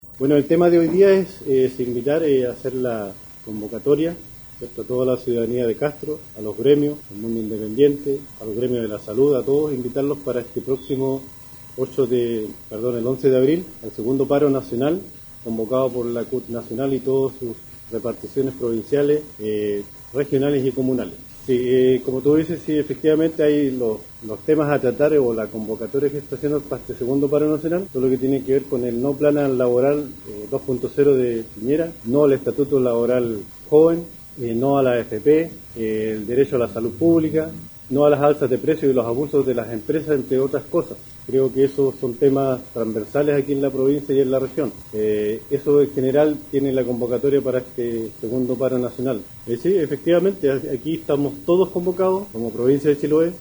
En la oportunidad, se llevo a cabo una conferencia de prensa en dependencias del Colegio de Profesores, donde estaban presentes también funcionarios de la salud pública, dirigentes de los trabajadores de las direcciones provinciales de educación, de la industria del salmón, y de manera muy especial, los empleados de centros comerciales, quienes si bien están desde hace mucho tiempo asociados a la CUT, es primera vez que se muestran apoyando firmemente el movimiento social convocado por la Central Unitaria para el día 11 de abril.